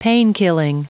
Prononciation du mot painkilling en anglais (fichier audio)
Prononciation du mot : painkilling